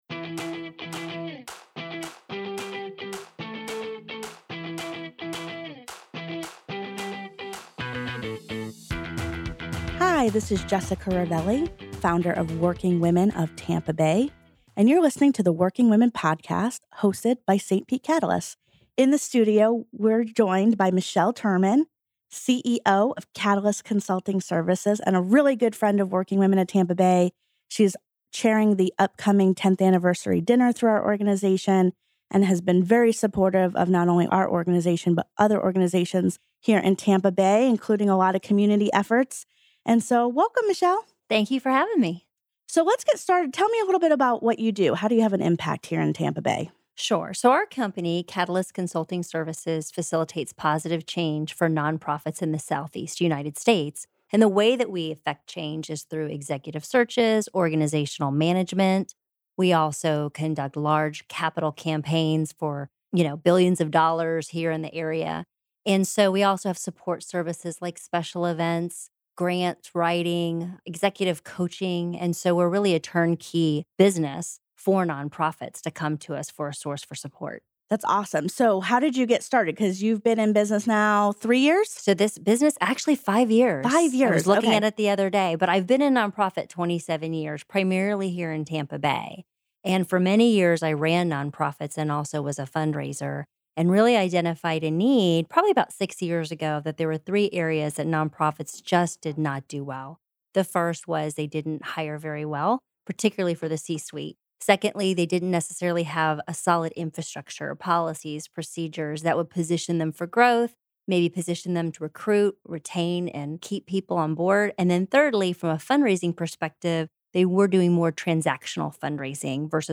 Click the arrow above to listen to the full long-form interview.